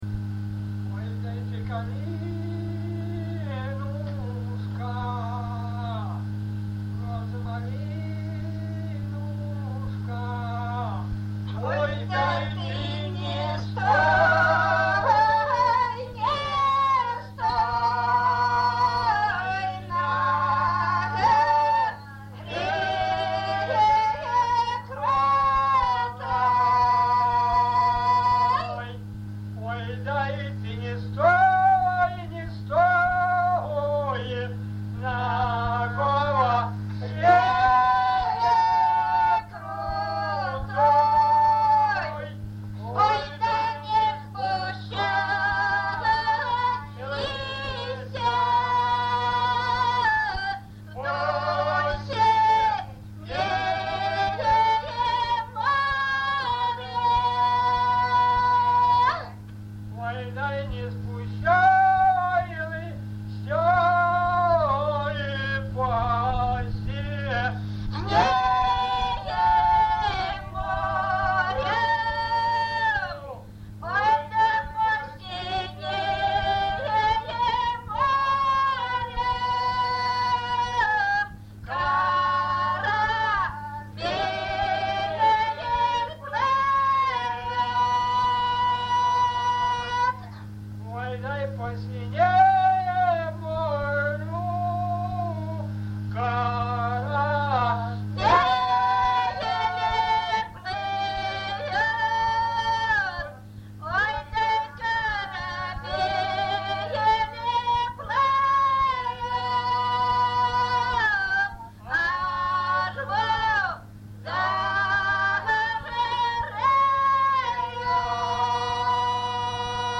ЖанрПісні з особистого та родинного життя, Солдатські